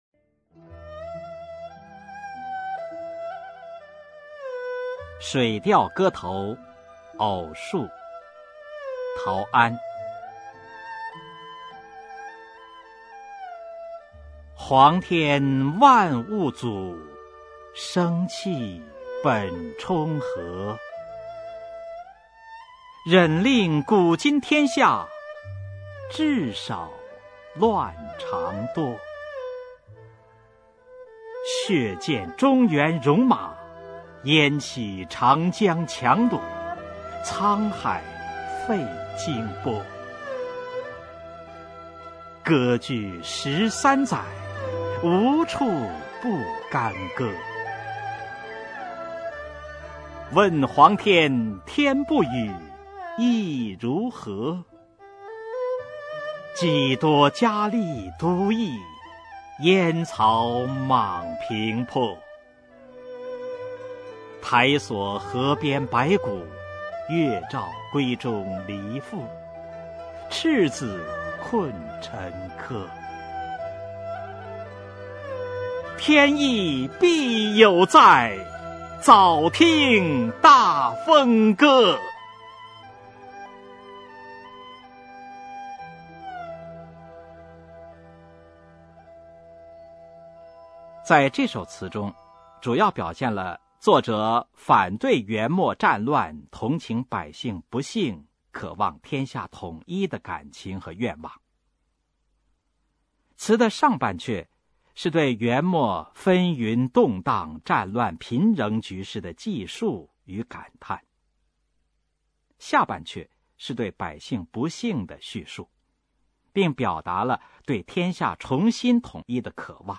[明代诗词诵读]陶安-水调歌头·偶述 朗诵